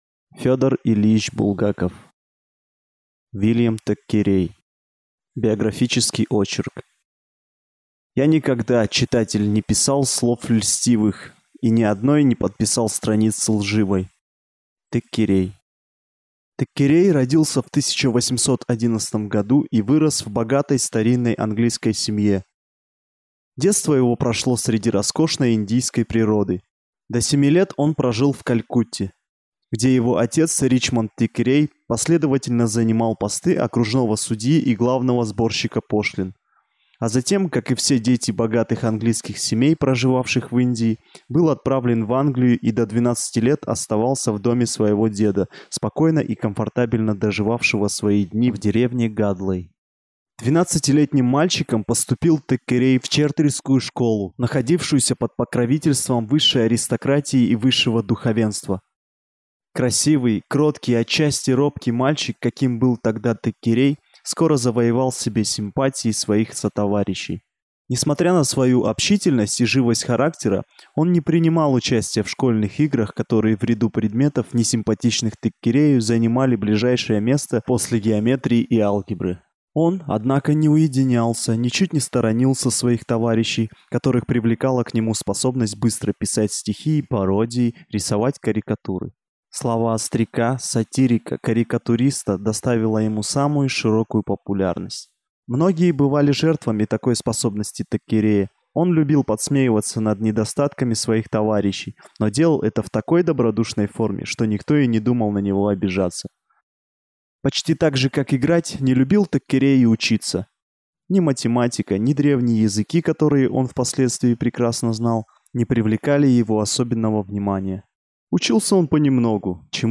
Аудиокнига В. Теккерей | Библиотека аудиокниг